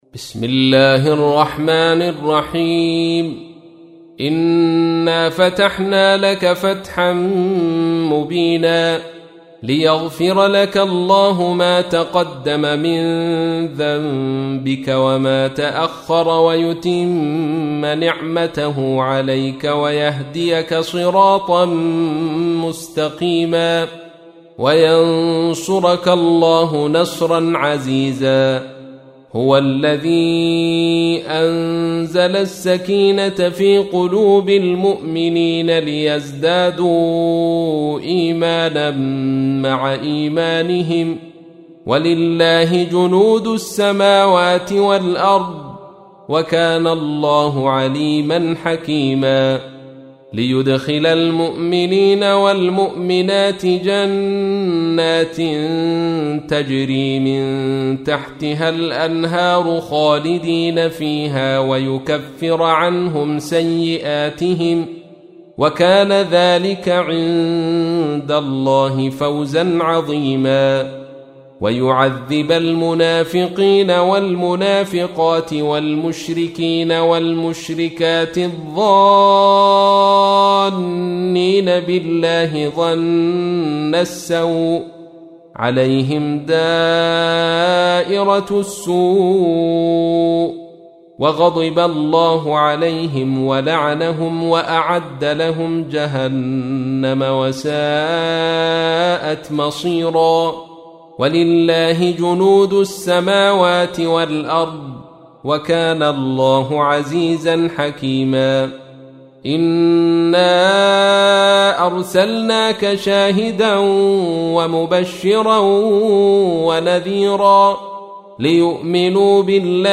تحميل : 48. سورة الفتح / القارئ عبد الرشيد صوفي / القرآن الكريم / موقع يا حسين